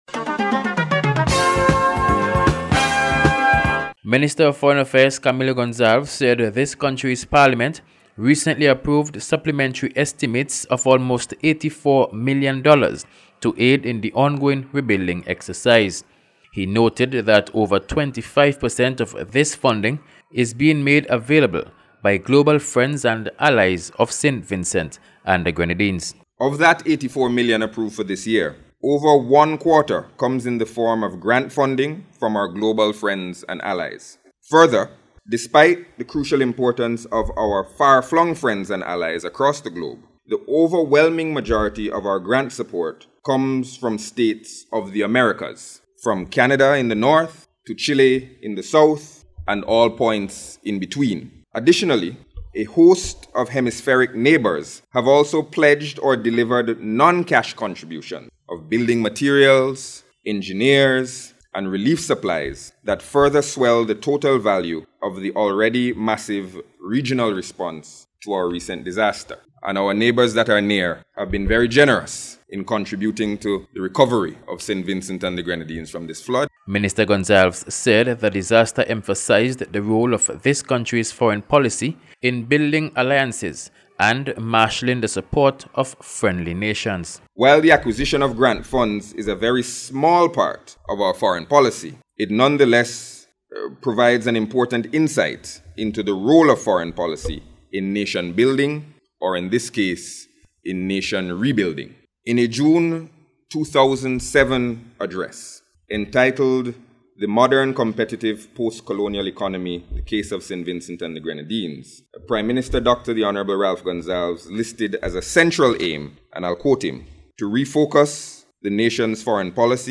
He made the point while speaking at last week’s launch of the Second Annual Inter-American Exhibition, held at the National Public Library.